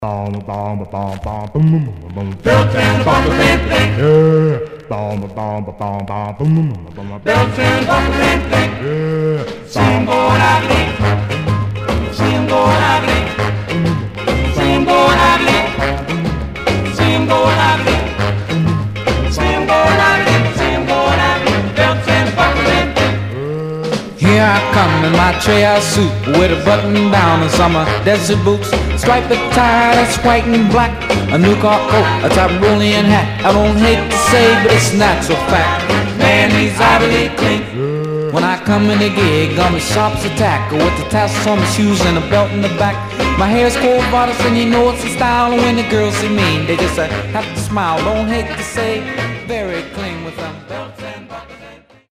Stereo/mono Mono
Male Black Group